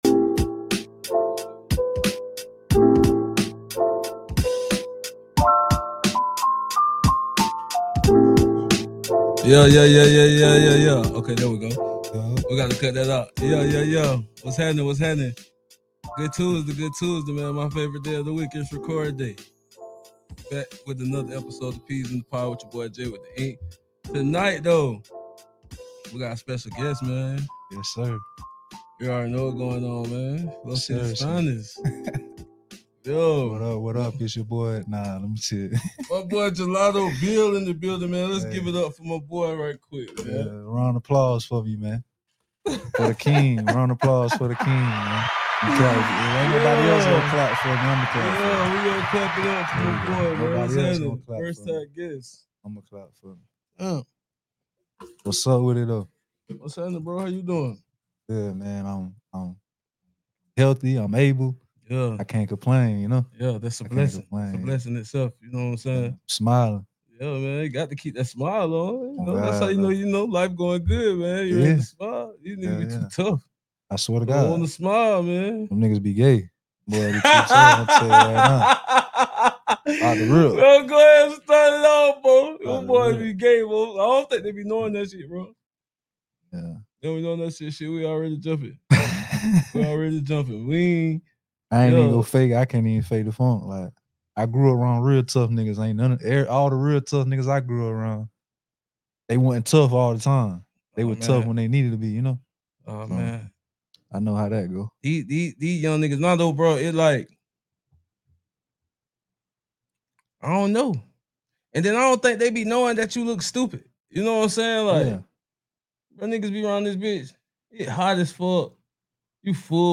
With personal stories, spirited debates, and unfiltered honesty, the two unpack myths around two-parent households, challenges faced by single parents, and the importance of being present regardless of circumstance. They also touch on the flawed college system, what real support looks like, and why accountability, not just presence, defines a good parent.